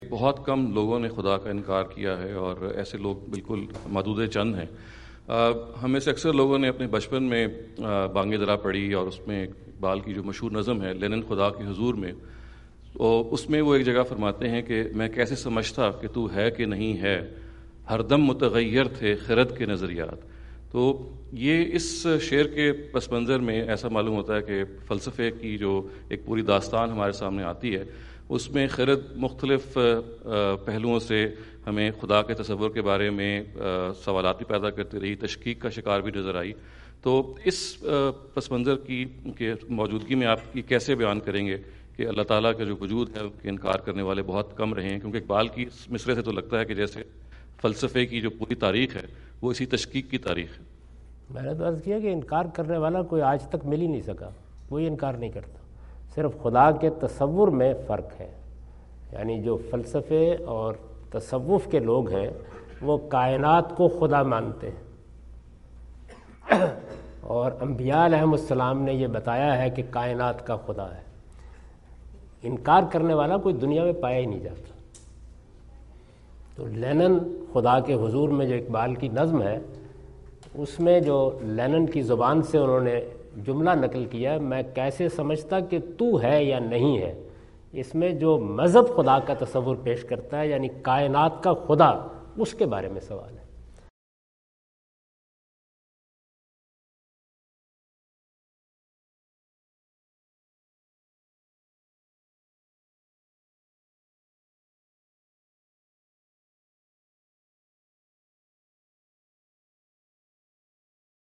Javed Ahmad Ghamidi answer the question about "Iqbal's Concept of God" asked at Aapna Event Hall, Orlando, Florida on October 14, 2017.
جاوید احمد غامدی اپنے دورہ امریکہ 2017 کے دوران آرلینڈو (فلوریڈا) میں "علامہ اقبال کا تصورِ خدا" سے متعلق ایک سوال کا جواب دے رہے ہیں۔